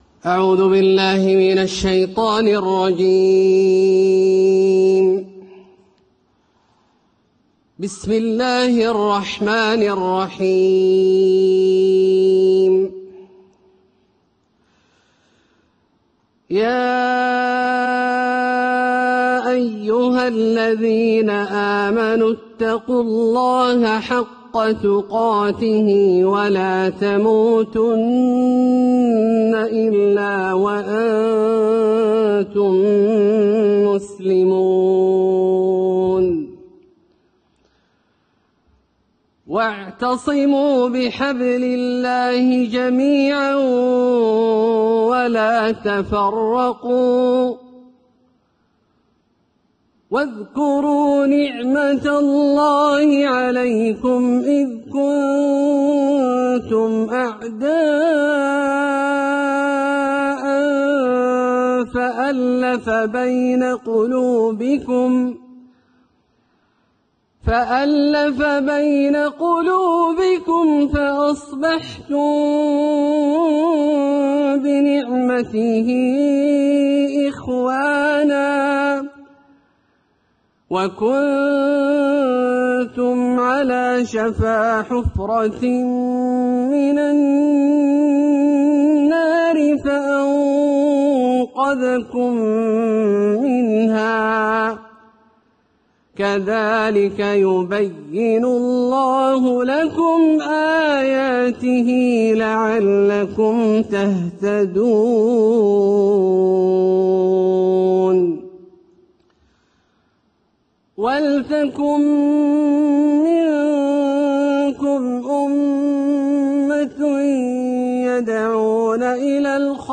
تلاوة فضيلة الشيخ أ.د. عبدالله الجهني في انطلاق مؤتمر بناء الجسور بين المذاهب الإسلامية | 6 رمضان 1446هـ.